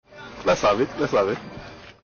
Lets-have-it-Lets-have-it-Comedy-sound-effect.mp3